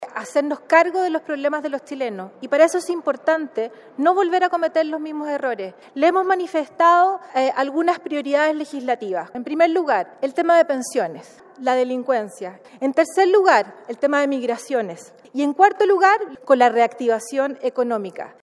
La jefa de bancada de la UDI, la diputada María José Hoffmann, aseguró que deben trabajar en una agenda legislativa enfocada en materias de pensiones, seguridad y migración.